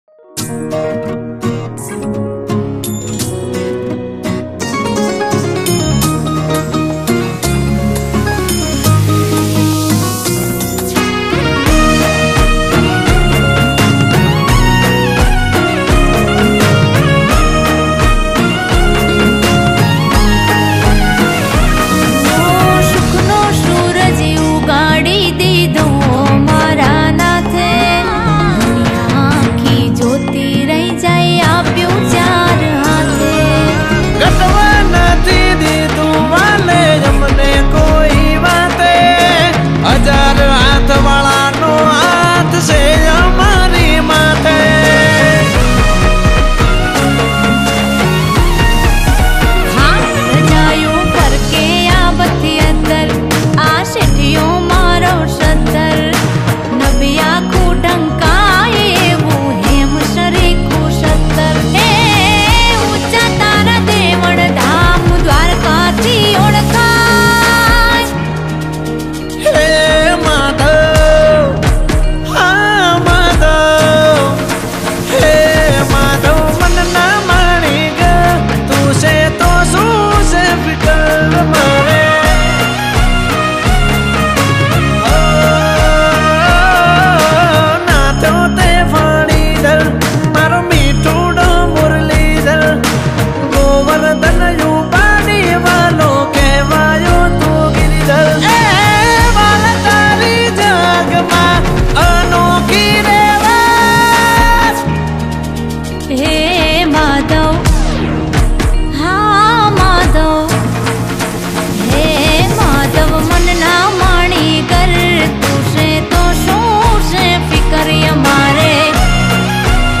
Gujarati Bhakti Song